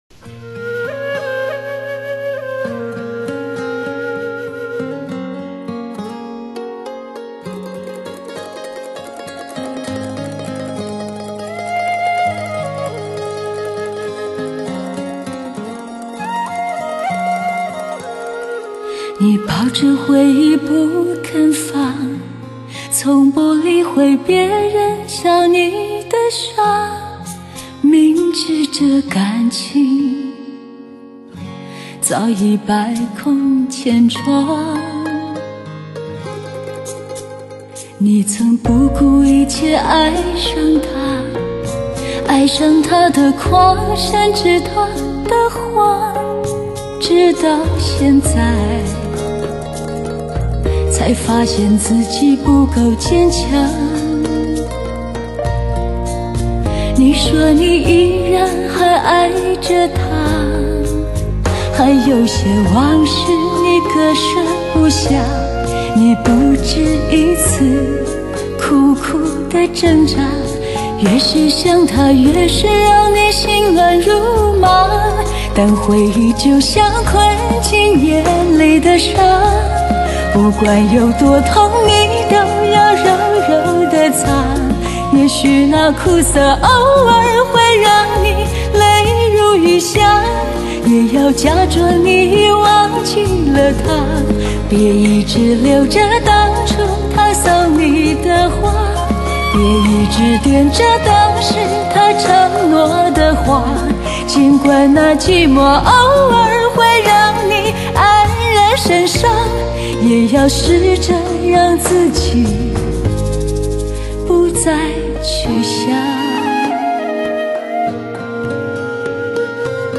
甜歌本色，淡淡的忧伤，深深的吻痕……
缠绵迷离——唱出多少柔情多少泪
发烧本色——淬取100%纯度最甜美的人声